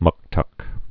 (mŭktŭk)